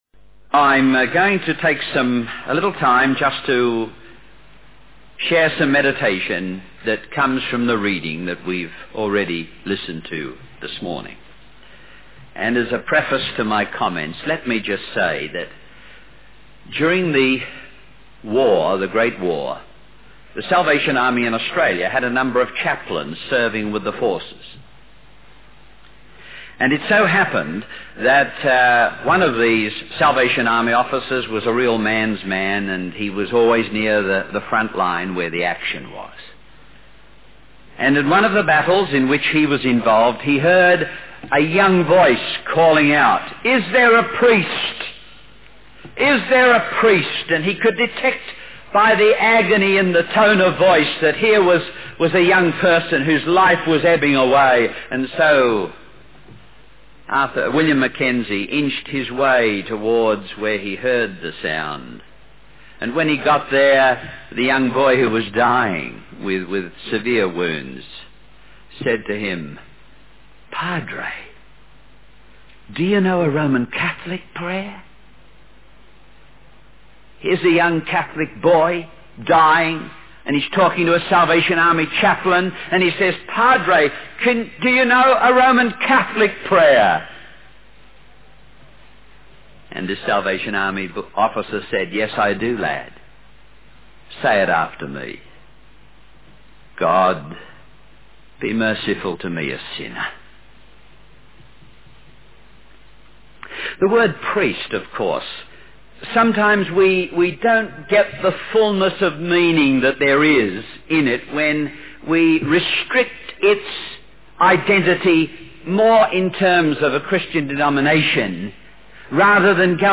In this sermon, the speaker emphasizes the importance of Christ in our lives. He highlights three key aspects of Christ: Christ as our leader, Christ as the truth, and Christ as the source of life.